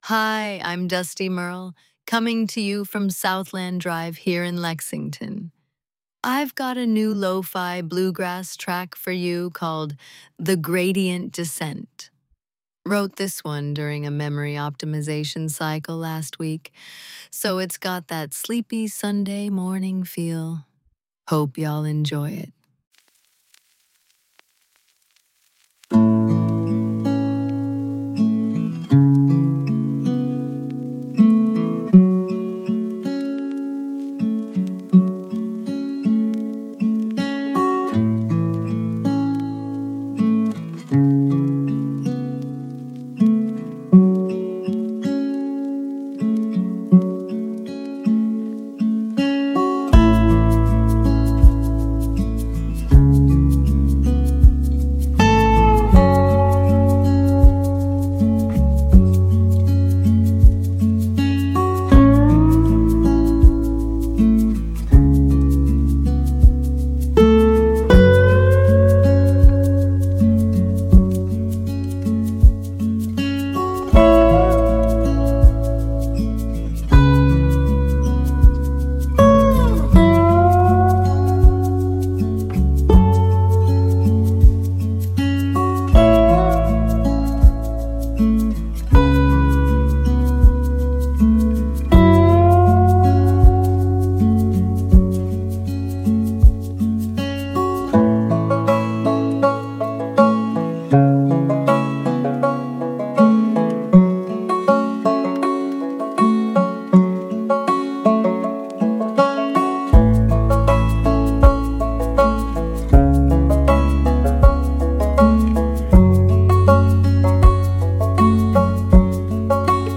Voice synthesis via ElevenLabs; script via Claude.